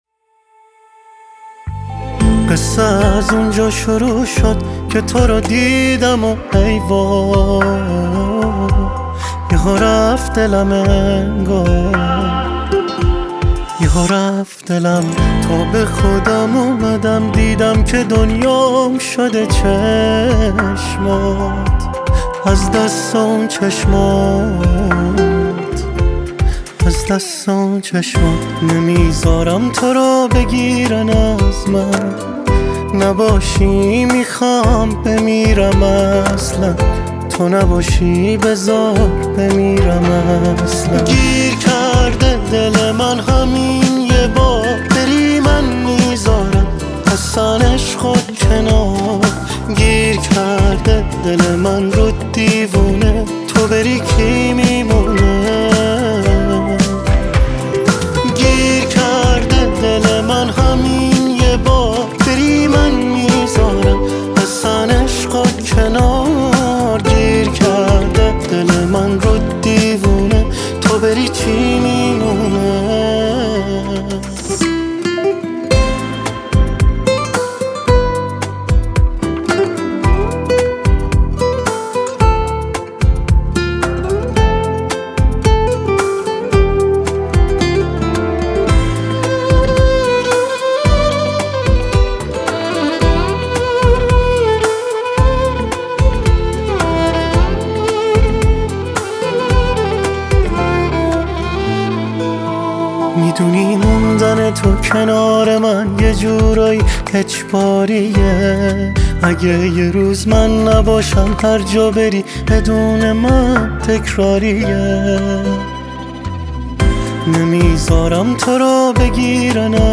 گیتار
ویولون